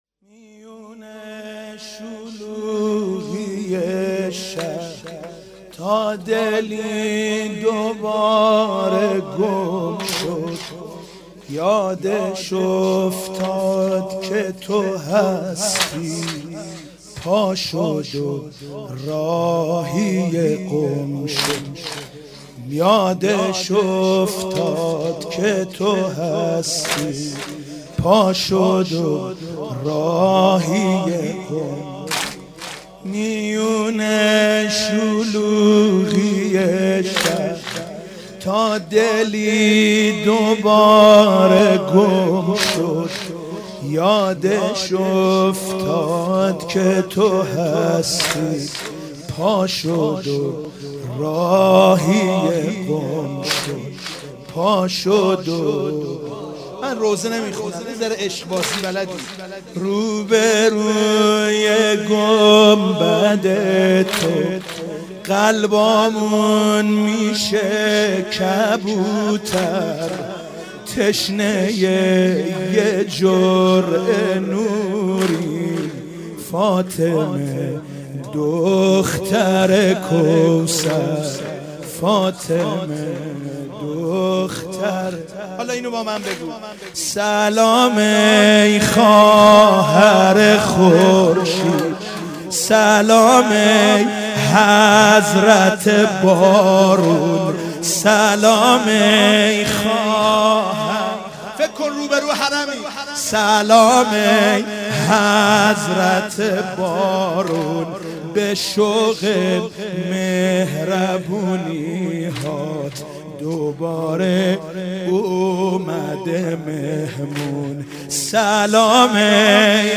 شهادت حضرت معصومه سلام الله علیها96 - واحد - میون شلوغی شهر
شهادت حضرت معصومه سلام الله علیها